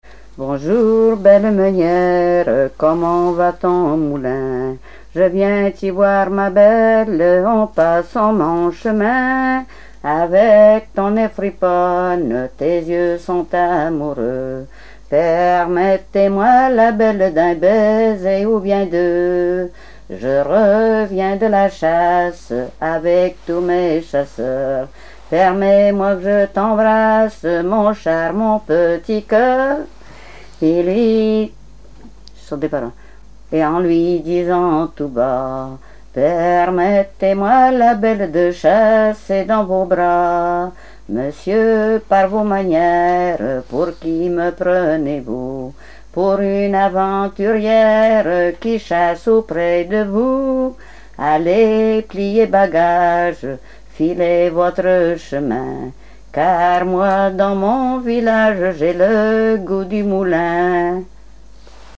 Genre dialogue
Répertoire de chansons traditionnelles et populaires
Pièce musicale inédite